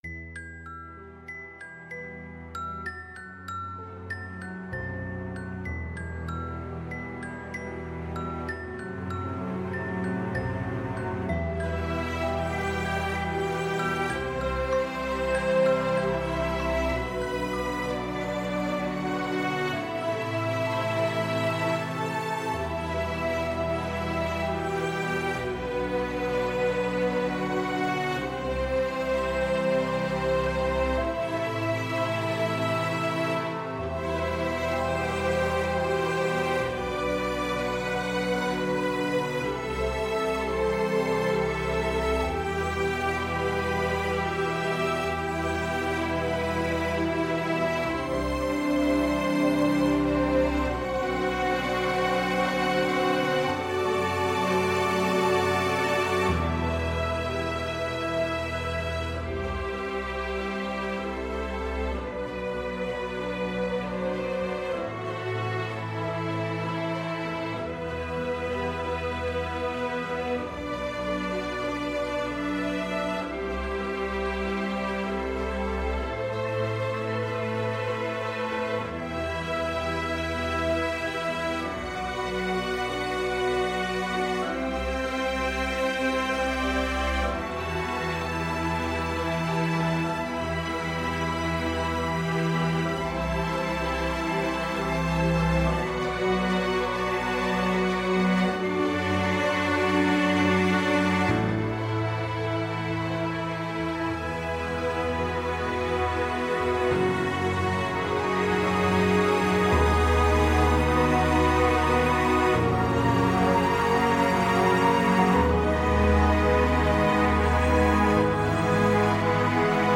Звучание сказочной мелодии